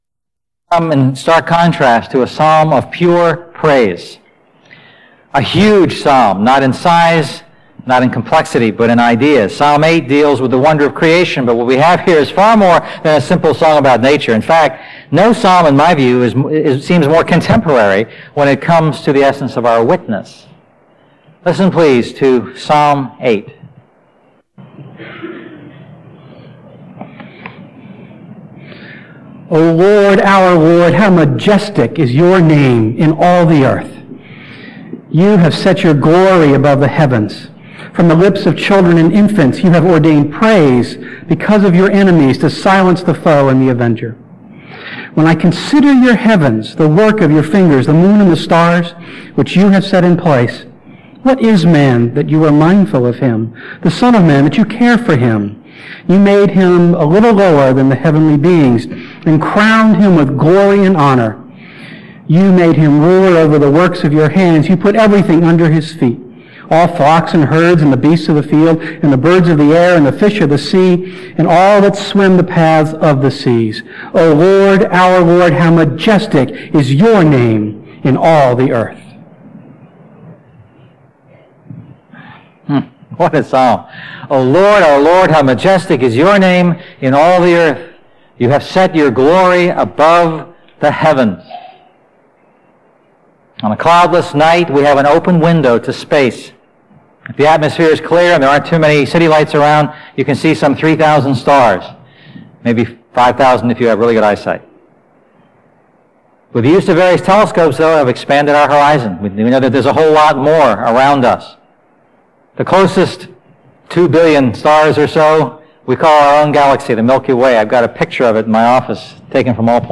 A message from the series "A New Song."